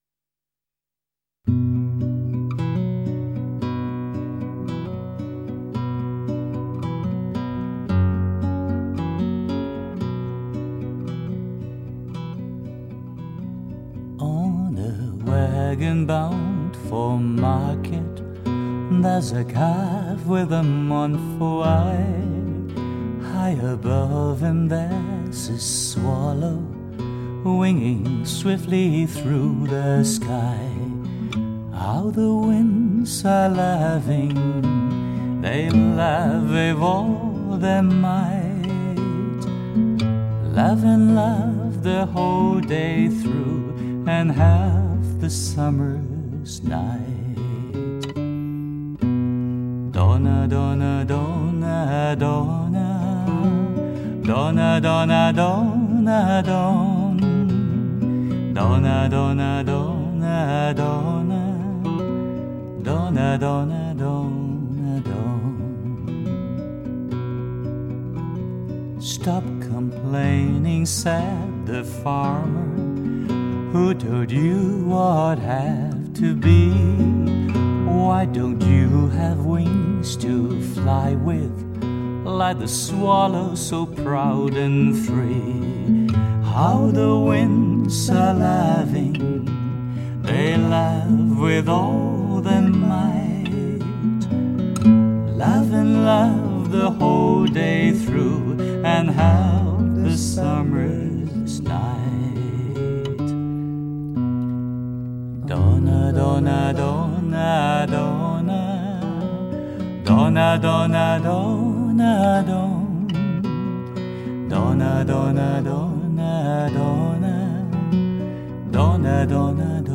简朴、清新的伴奏，恰到好处；浑厚、干净的声音，是那么完美，有的甚至比原唱更精致。